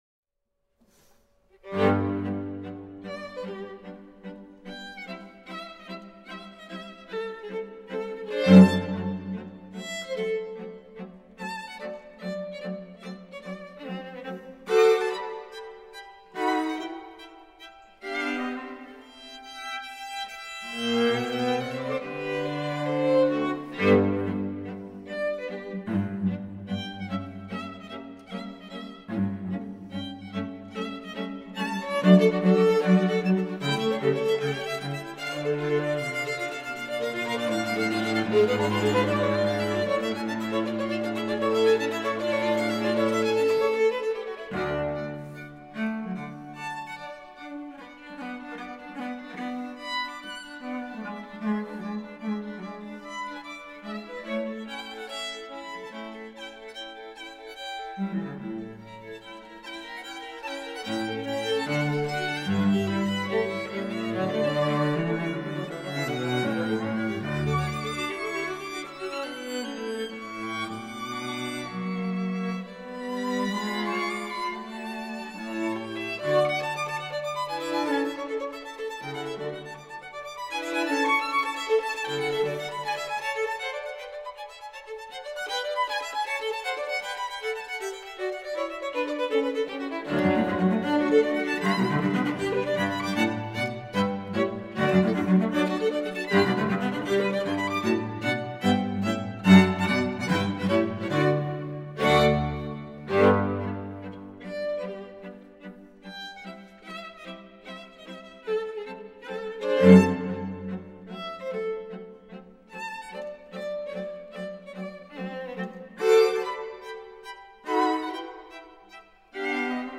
String Quartet No. 66 in G major, Op. 77, No. 1, Hob.III:81, “Lobkowitz”
I. Allegro moderato, arr. R. Dubinsky